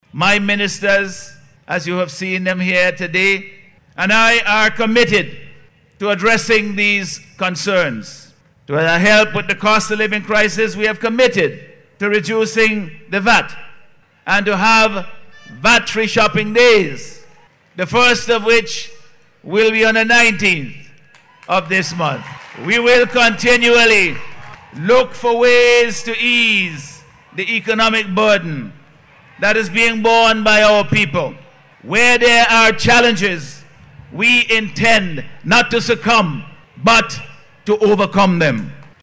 This was announced by Prime Minister and Minister of Finance, Dr. Godwin Friday at yesterday’s swearing in ceremony.